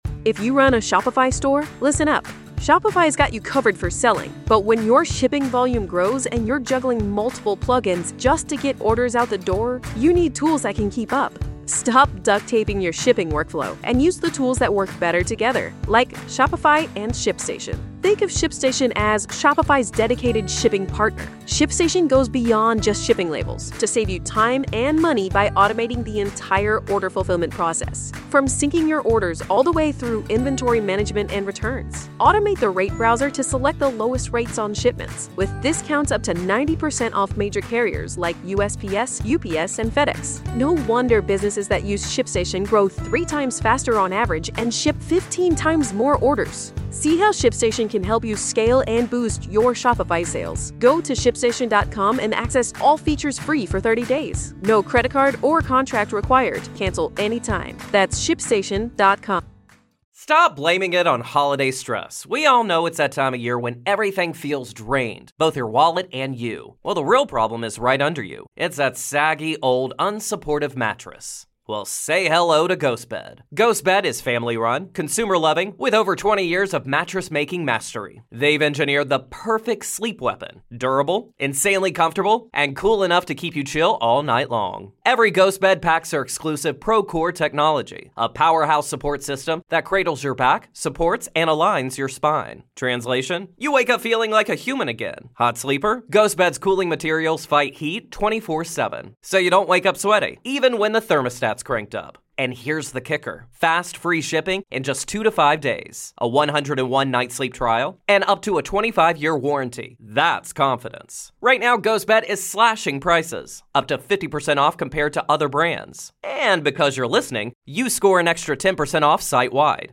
Each recording features the full day’s testimony, witness questioning, objections, rulings, and all live developments direct from the courtroom — presented exactly as they happened, without edits or commentary.